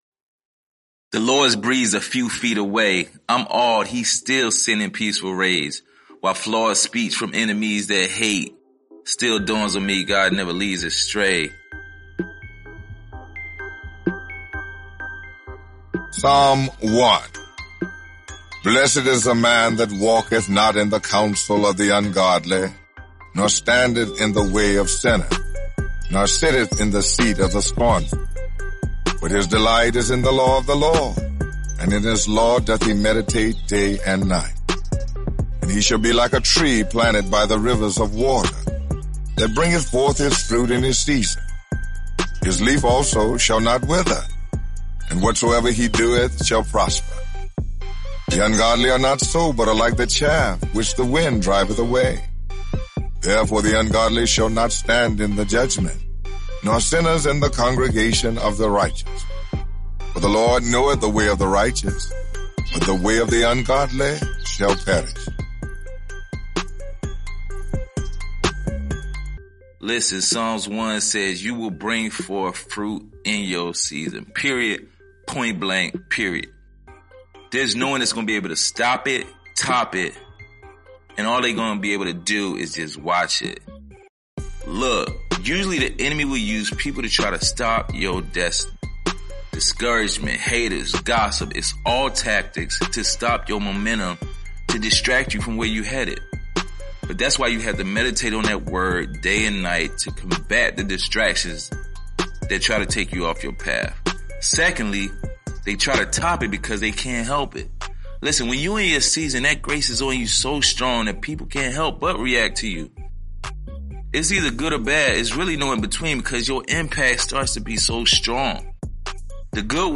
Experience the Psalms in a fresh new way! This narrated devotional provides encouraging insight over original music that injects hope, faith, wisdom, inspiration, and so much more through the Psalms!